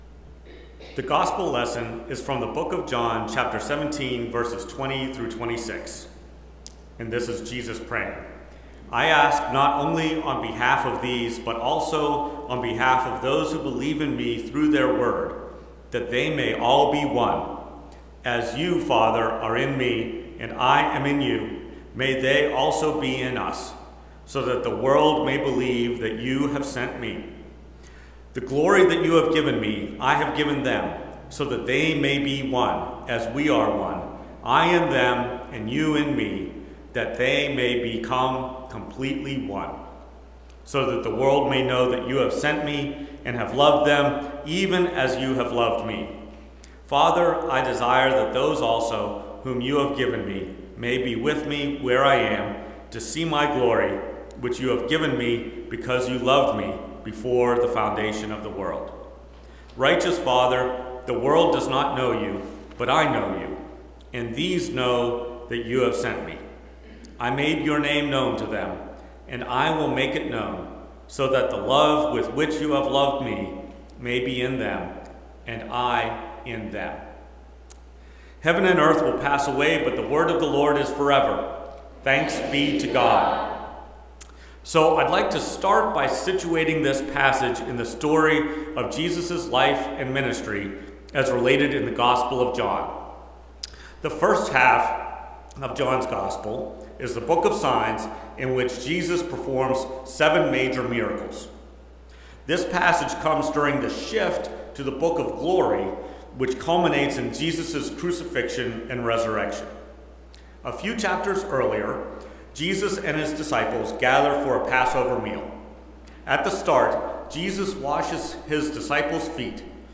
Preached at First Presbyterian Church of Rolla on June 1, 2025. Based on John 17:20-26.